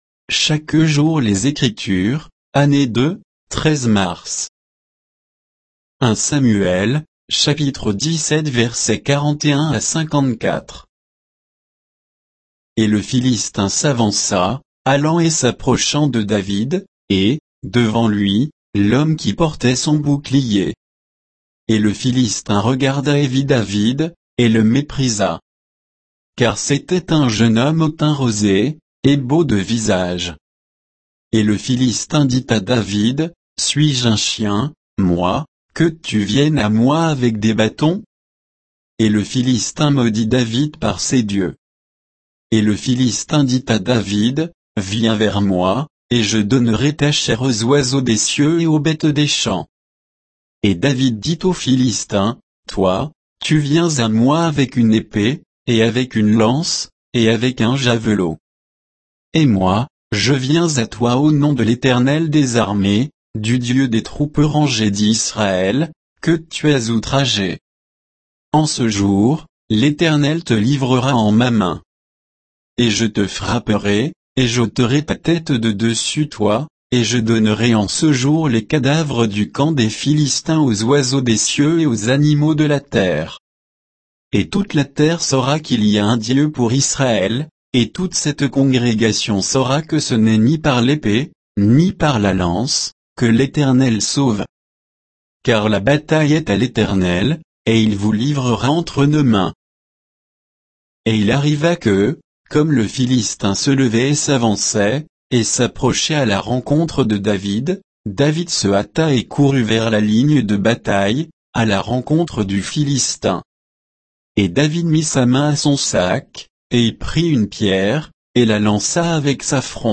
Méditation quoditienne de Chaque jour les Écritures sur 1 Samuel 17, 41 à 54